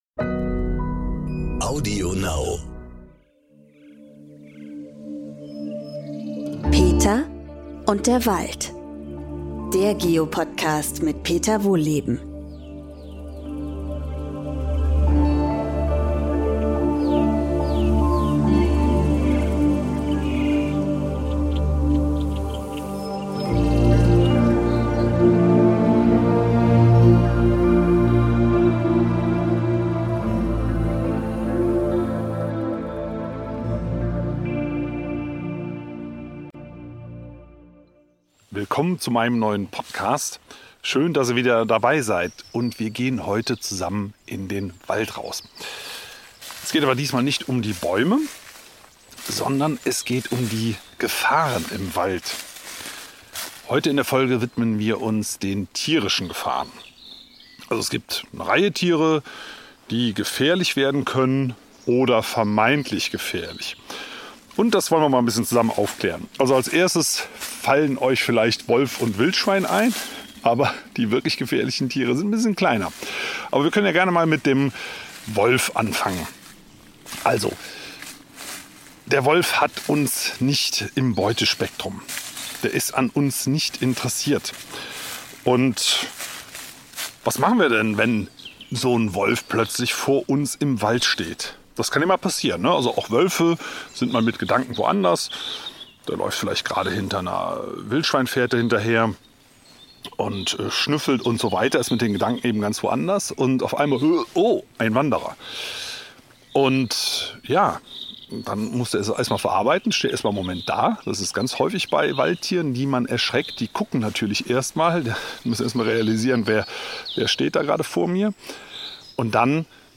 Beschreibung vor 4 Jahren In dieser Folge nimmt Peter Wohlleben uns wieder mit in den Wald. Bei einem ausgiebigen Spaziergang lernen wir alles über die tierischen Gefahren, die dort lauern können und warum die kleinsten Tiere die gemeinsten sein können.